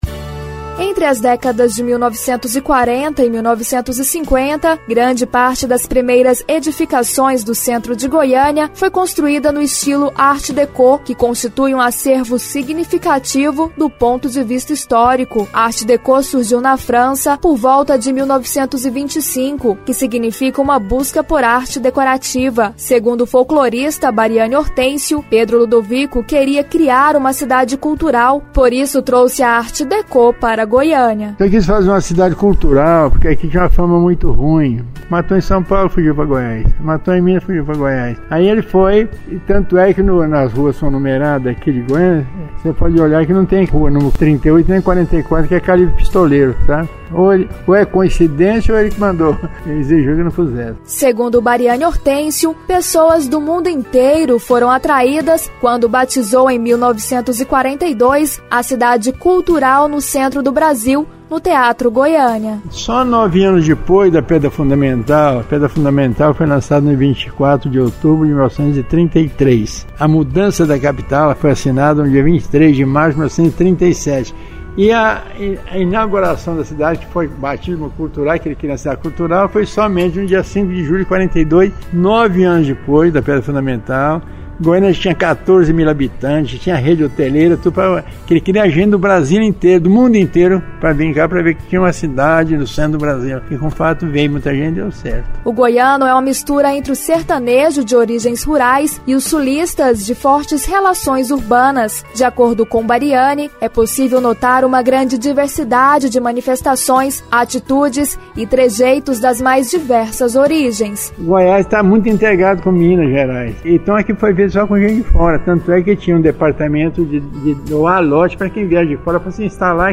Reportagem com a repórter